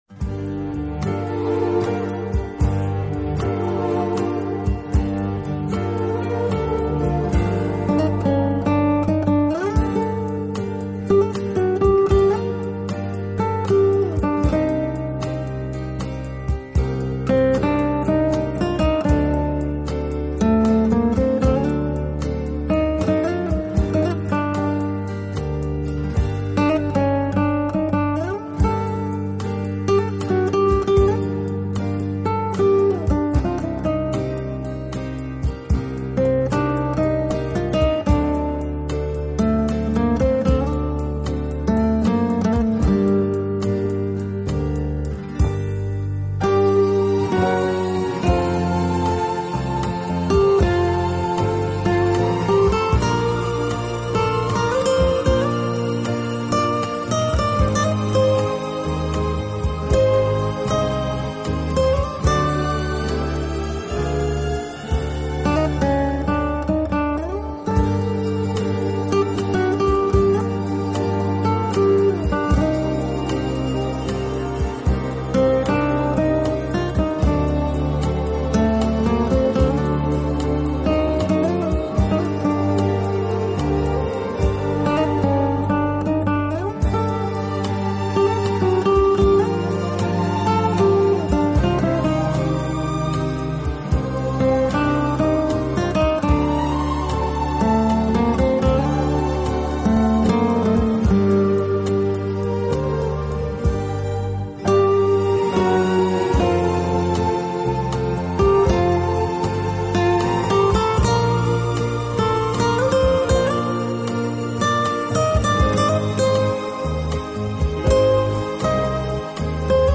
Genre: Instrumental, Classical